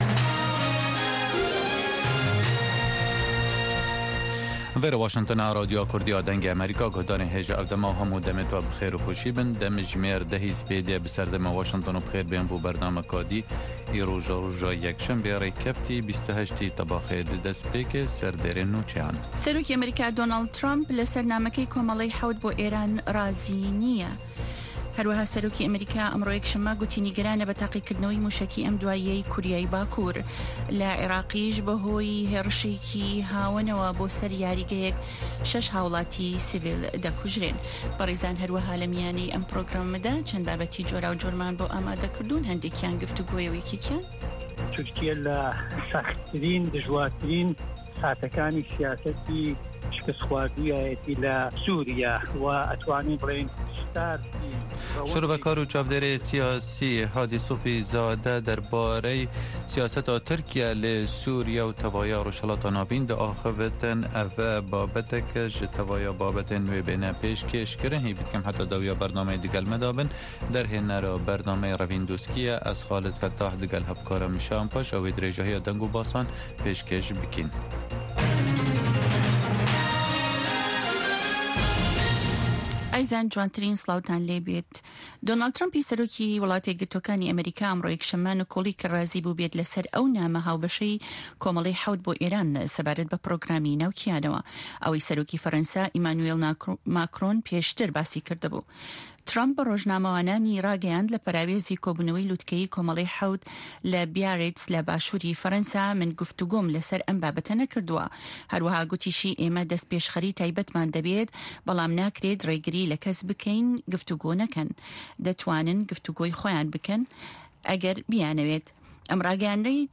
هه‌واڵه‌کان ، ڕاپـۆرت، وتووێژ، مێزگردی هه‌فته‌.